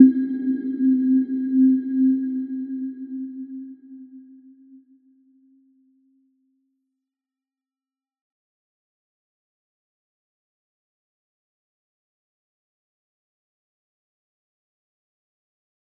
Little-Pluck-C4-mf.wav